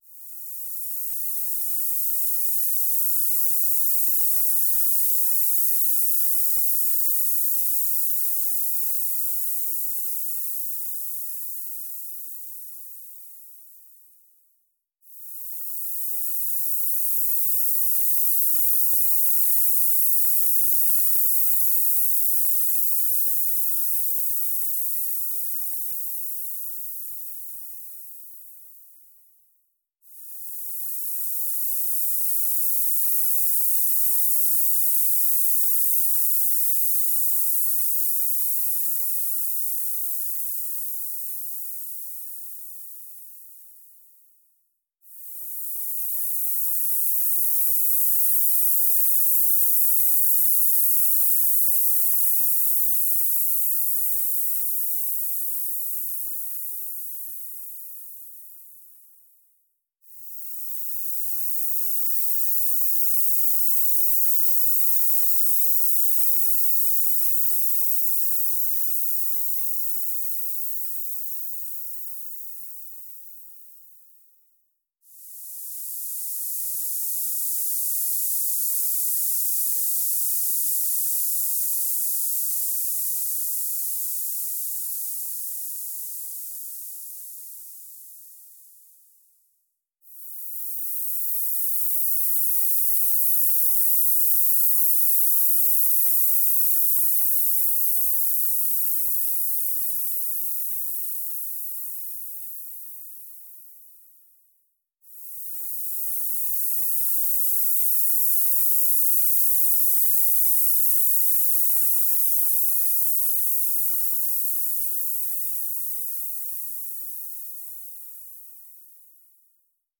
electroacoustic music